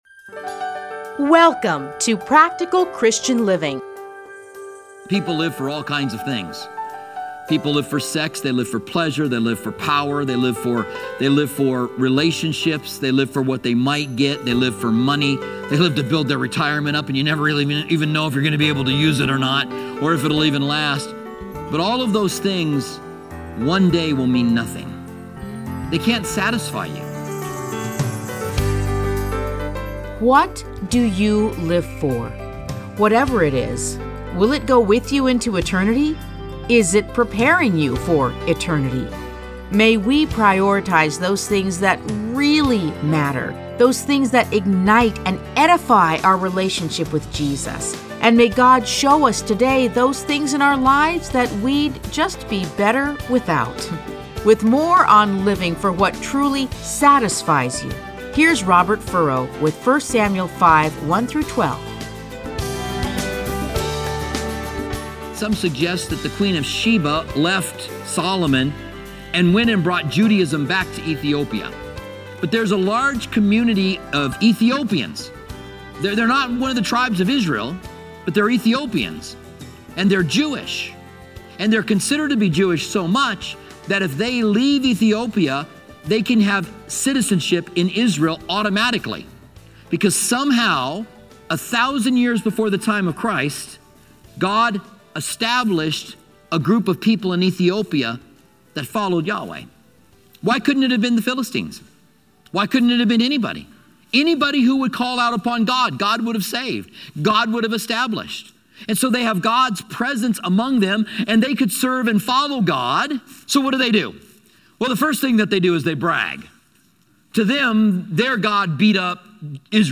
Listen to a teaching from 1 Samuel 5:1-12.